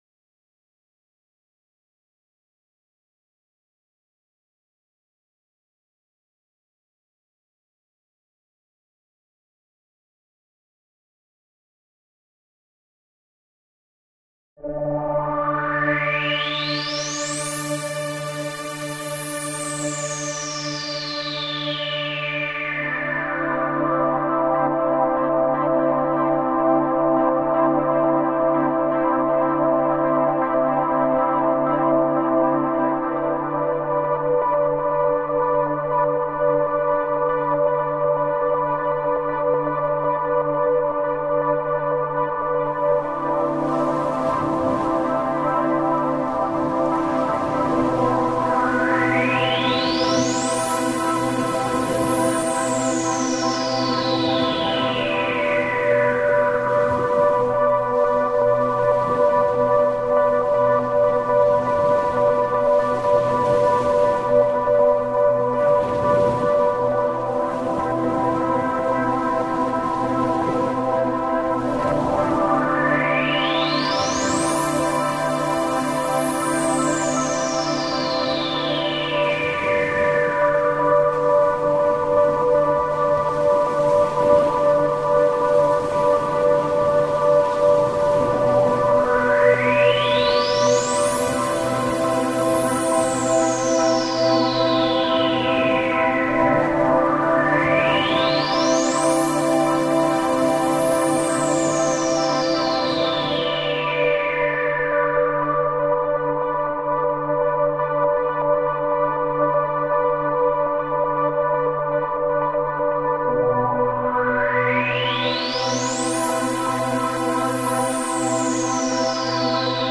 This last type of healing meditation is best performed while wearing headphones.
Please click on the sound file above and don a set of headphones to experience the binaural beat to heal the heart chakra, which spins at 12 Hz. (It starts on 15)
Flower-of-Life-Meditation-Heart-Chakra-Geometry-School.mp3